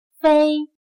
/fēi/No; al contrario